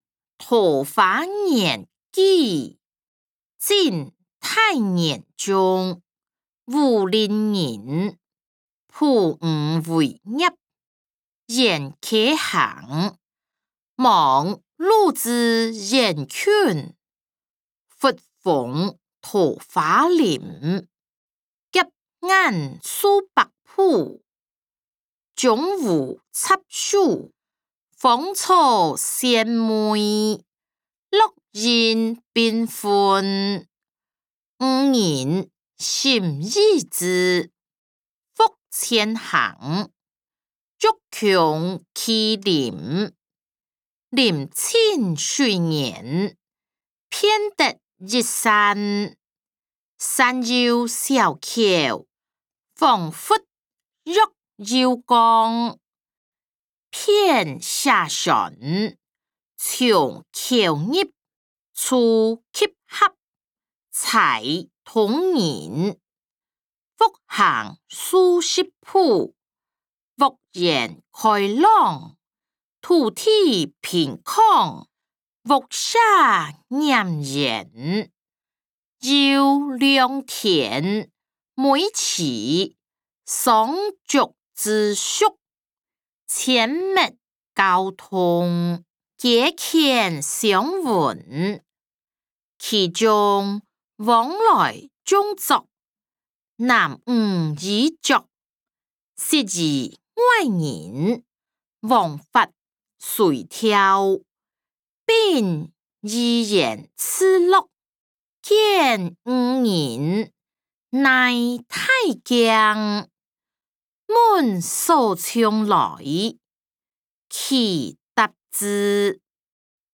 歷代散文-桃花源記音檔(大埔腔)